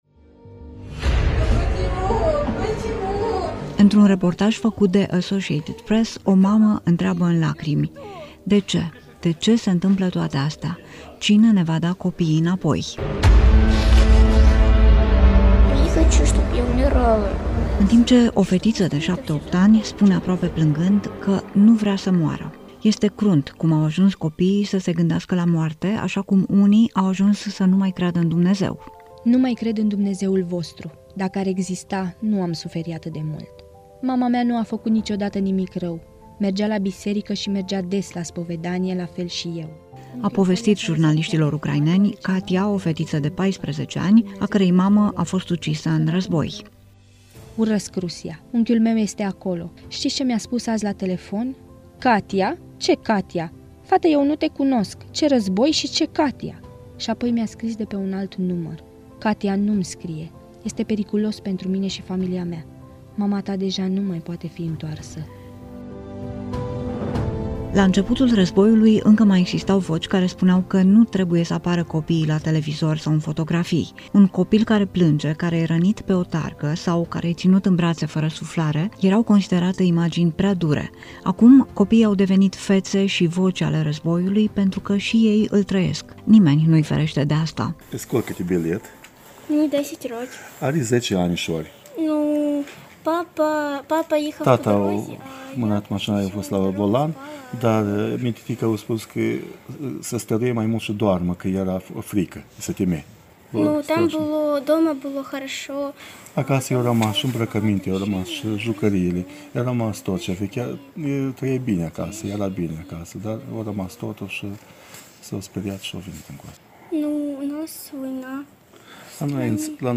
Reportaj-Copiii-si-razboiul.mp3